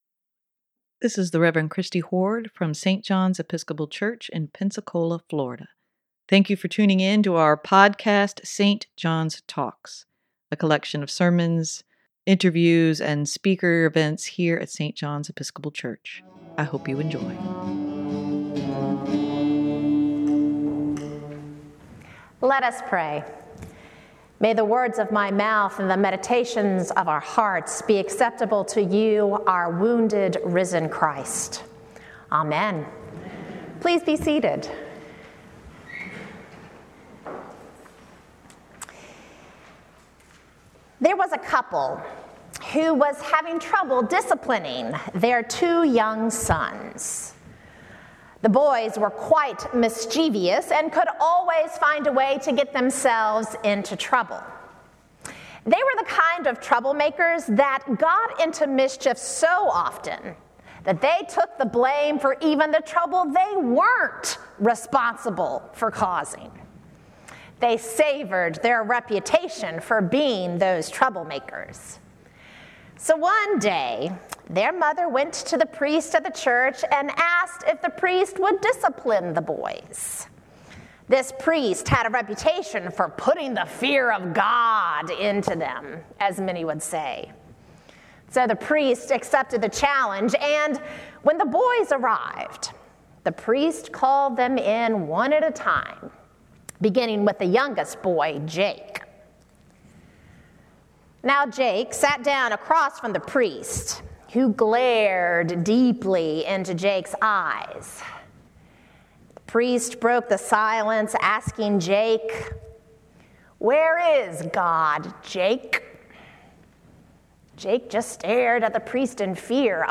Sermon
sermon-4-16-23.mp3